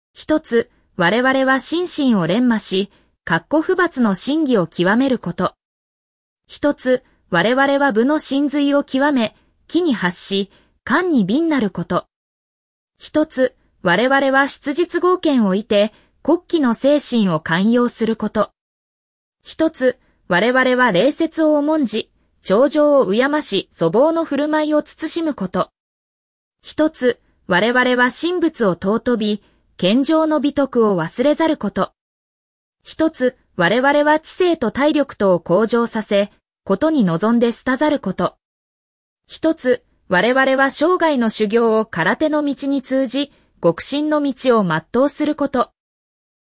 Dojo Kun in Japanese
At the end of each training session, the class lines up in seiza (kneeling), and the highest-ranked student or instructor calls out each line, which the class repeats in unison.
Note that Hitotsu (One) is said before each line to remind everyone that each rule is number one as all are equally essential.
Dojo-Kun-Japanese.mp3